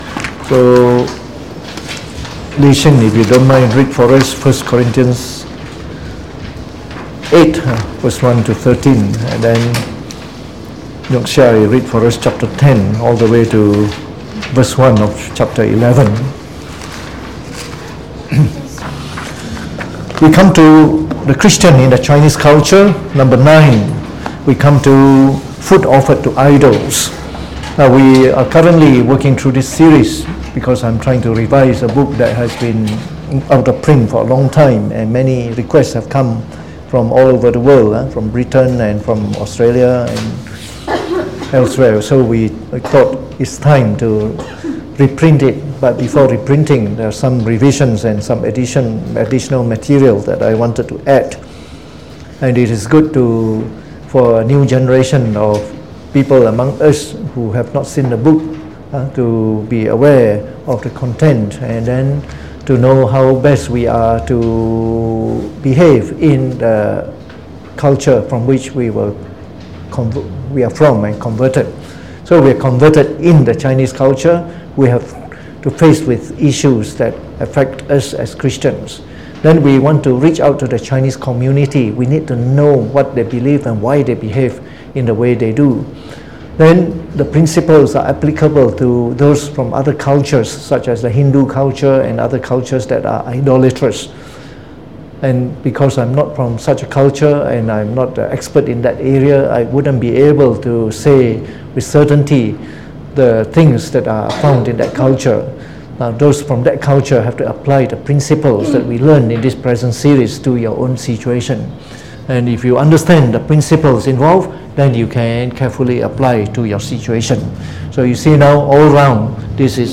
Delivered on the 30th of October 2019 during the Bible Study, from the series on The Chinese Religion.